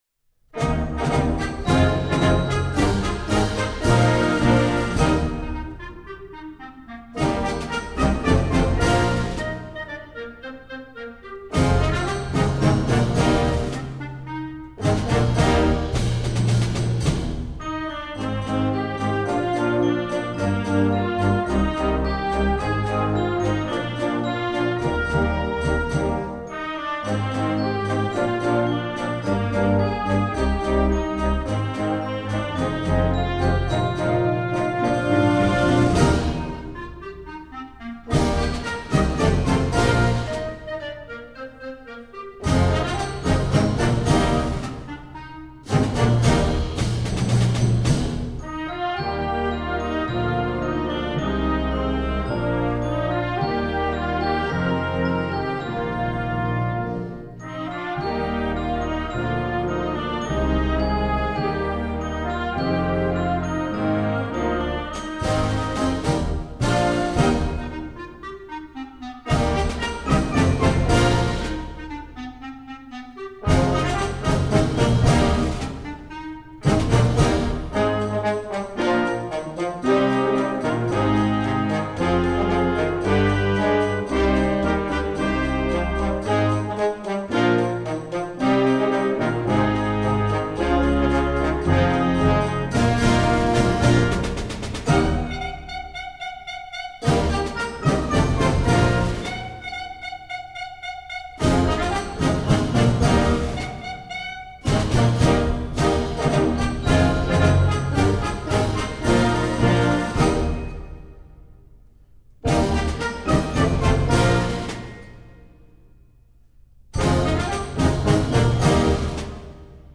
Voicing: Solo / Ensemble w/ Band